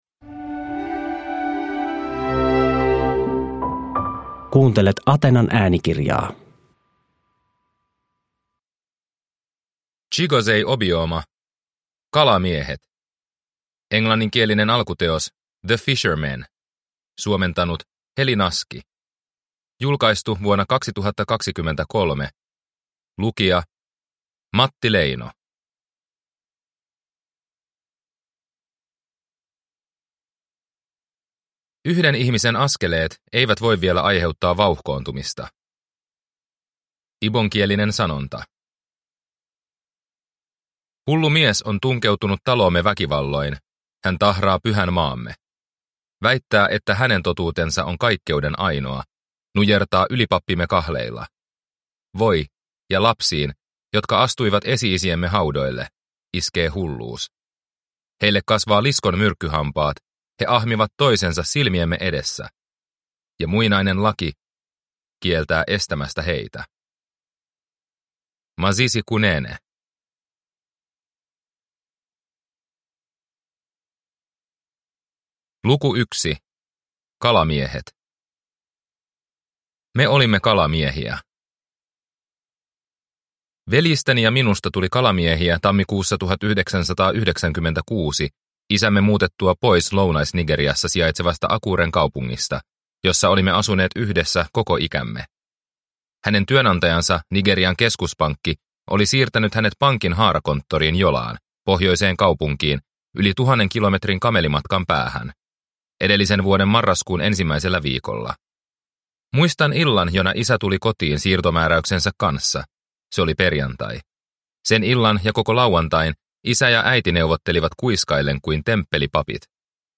Kalamiehet – Ljudbok – Laddas ner